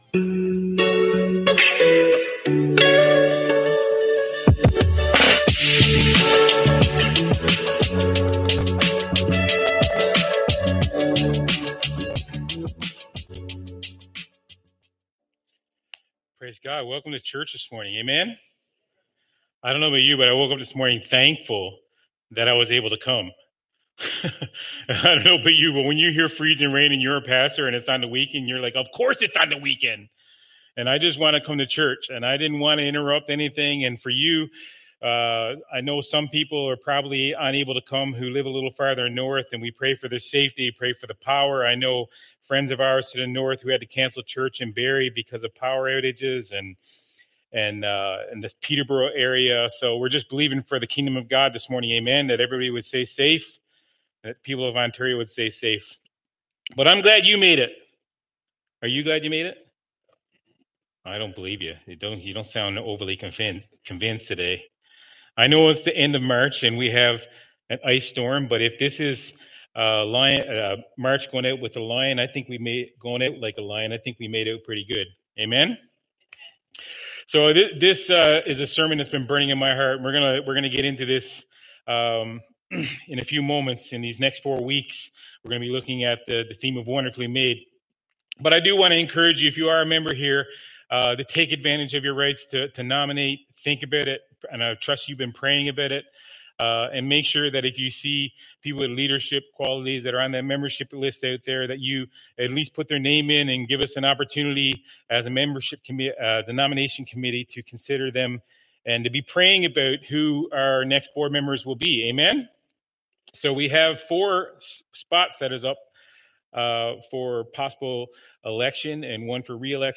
Sermons | Warden Full Gospel Assembly